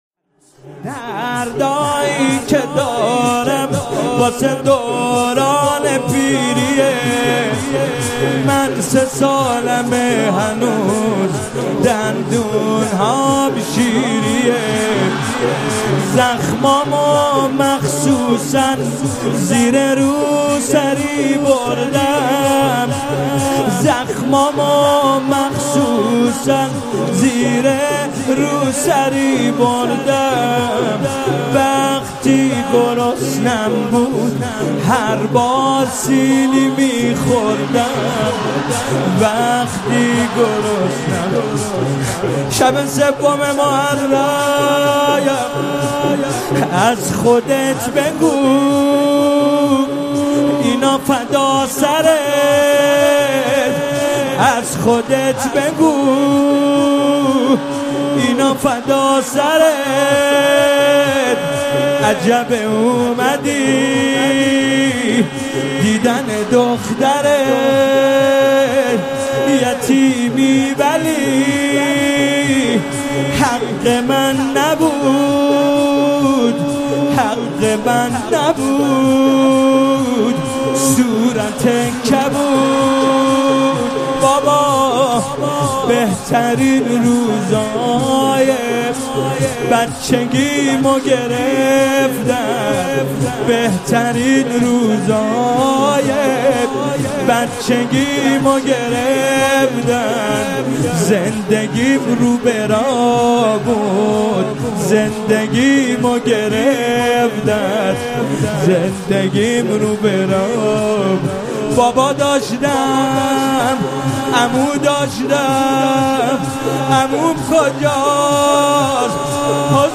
مداحی محرم ۱۴۰۲ شب سوم